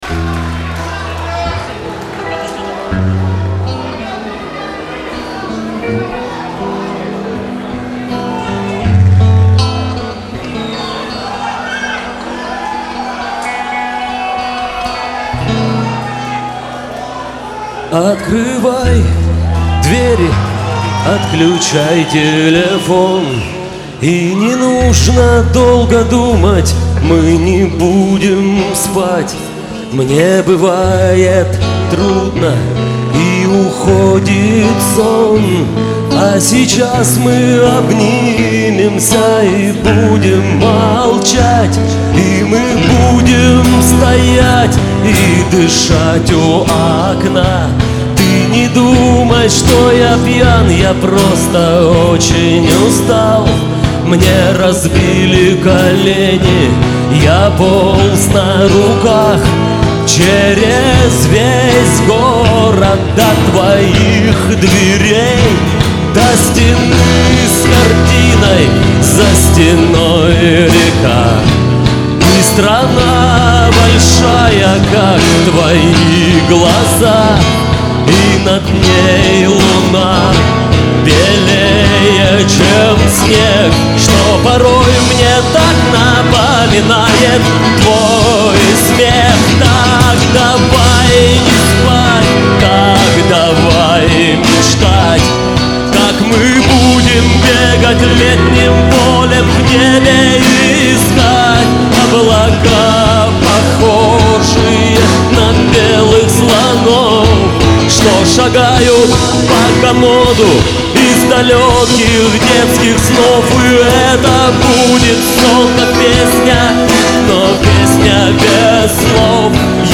вокал, ак. гитара